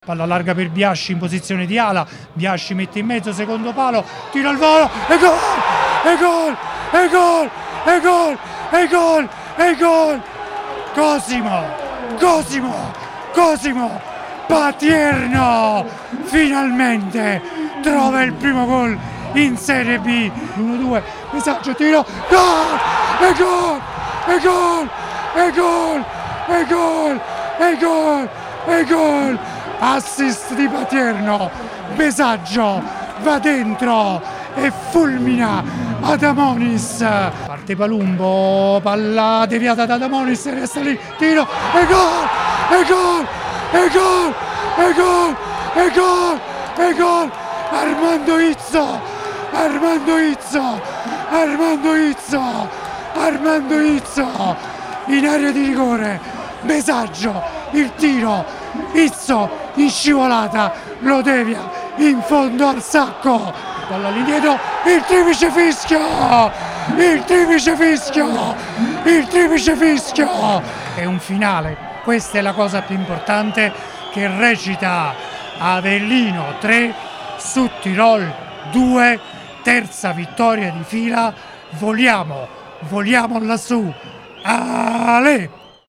Avellino-Südtirol 3-2: i gol di Patierno, Besaggio e Izzo